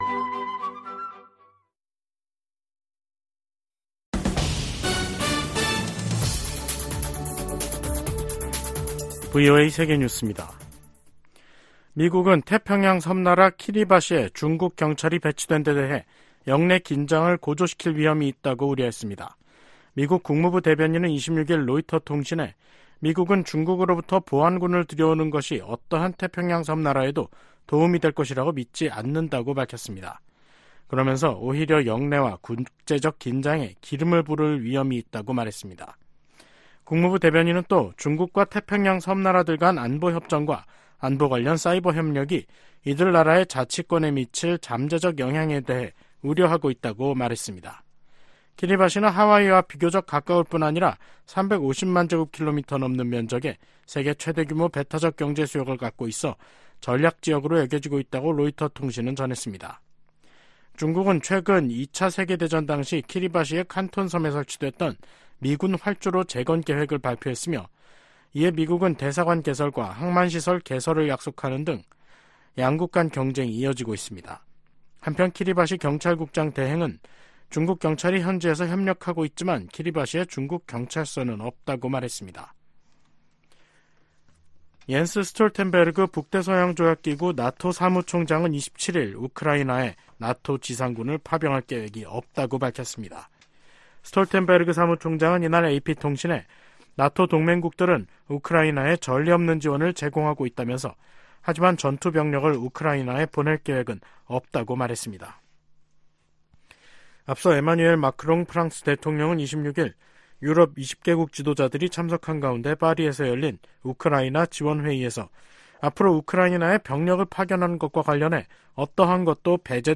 VOA 한국어 간판 뉴스 프로그램 '뉴스 투데이', 2024년 2월 27일 3부 방송입니다. 제네바 유엔 군축회의 첫날 주요국들이 한목소리로 북한의 핵과 미사일 개발을 강력히 비판했습니다.